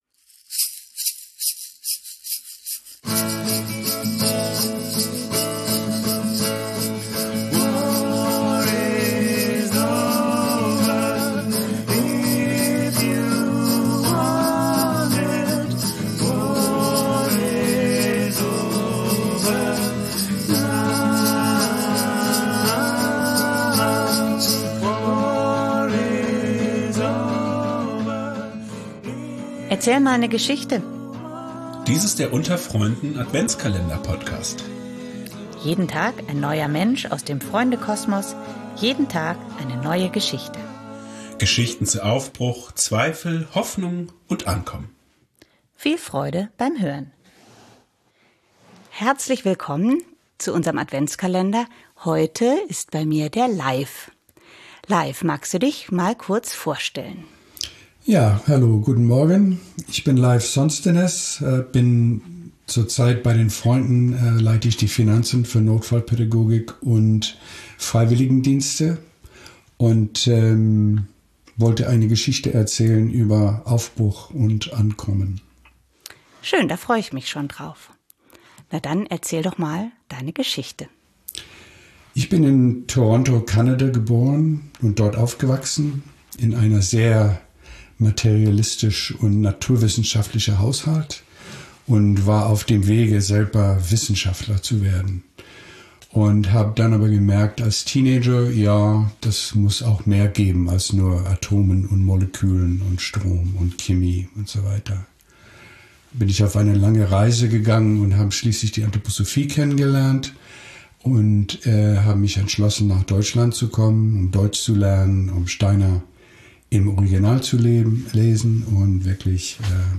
In unserem Adventskalender zum Hören erzählt jeden Tag eine andere Person aus dem Freund*innen-Kosmos eine Geschichte, die mit einem der vier Themen – Aufbruch, Zweifel, Hoffnung, Ankommen – zu tun hat.